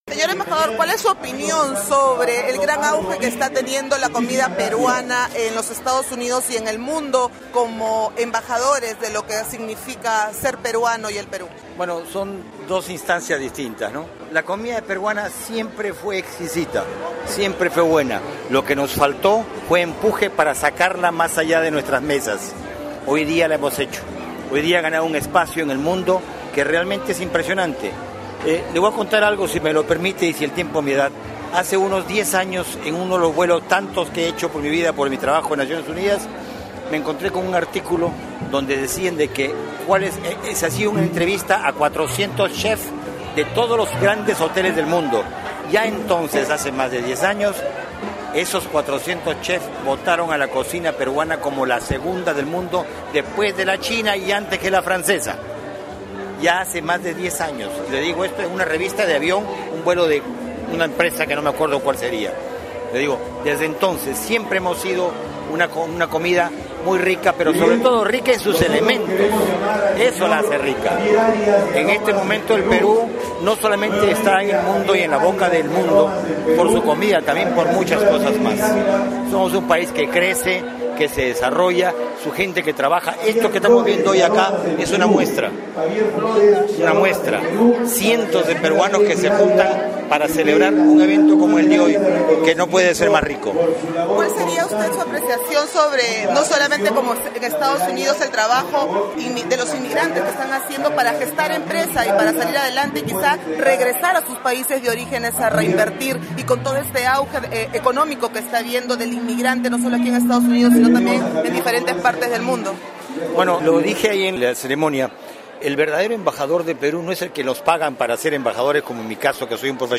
Entrevista al embajador de Perú ante la ONU, Enrique Román-Morey